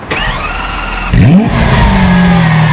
Index of /server/sound/vehicles/vcars/lamboaventadorsv
start.wav